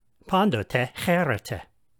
pantote chairete